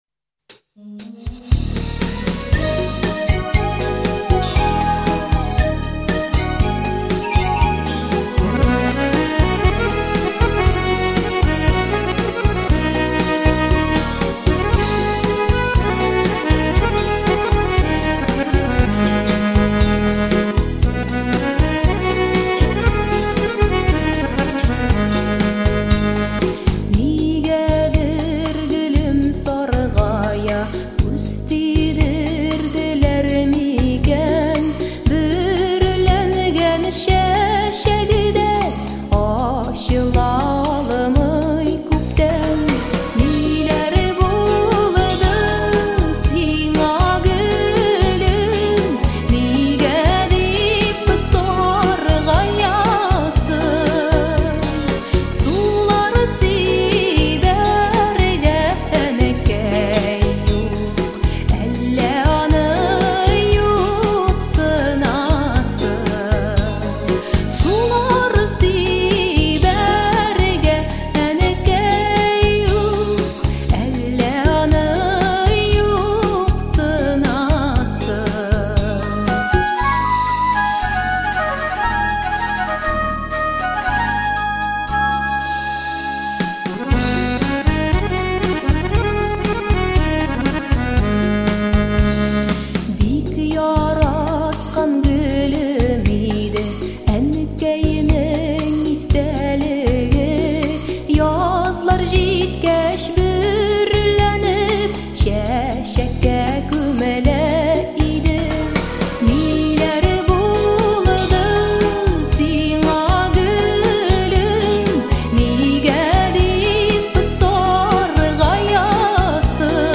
• Категория: Детские песни
татарские детские песни